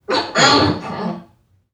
NPC_Creatures_Vocalisations_Robothead [36].wav